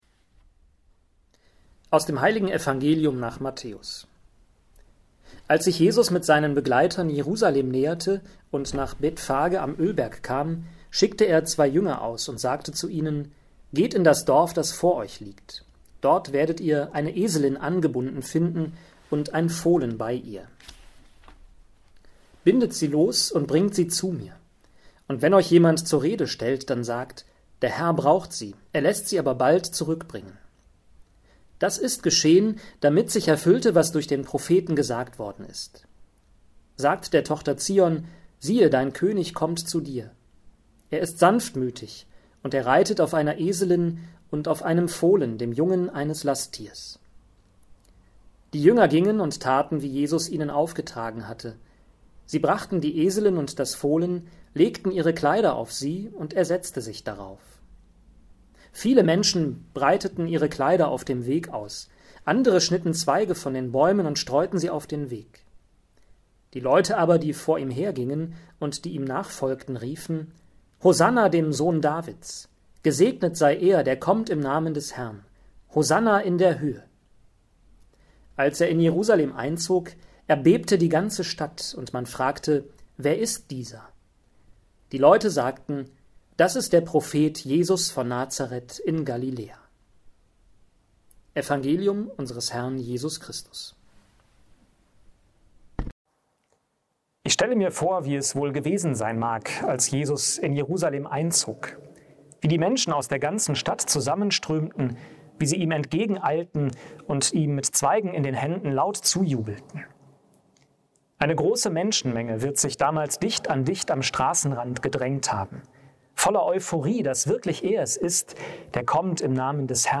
Impuls zum Palmsonntag